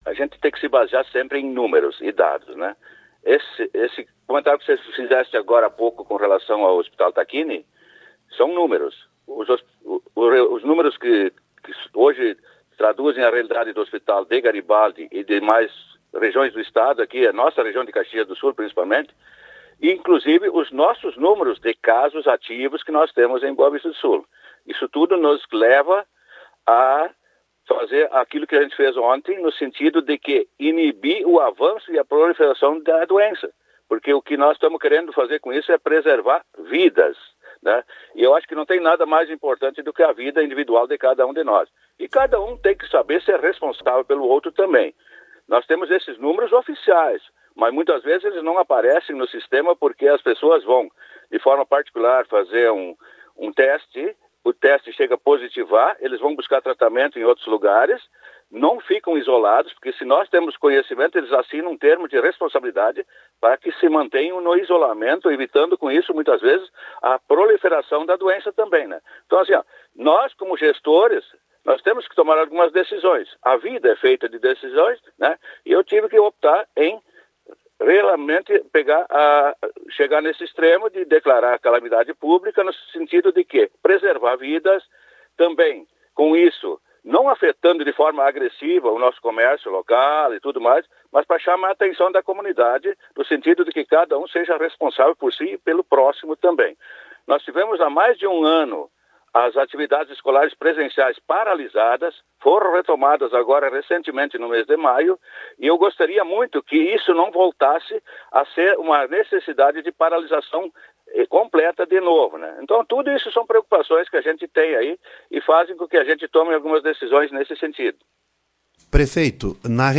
Em entrevista à Rádio Garibaldi, o prefeito Roberto Martim Schaeffer (PSDB), comentou que a decisão foi adotada como forma de inibir o avanço do vírus na cidade e reforçar com os moradores os cuidados coletivos essenciais neste momento.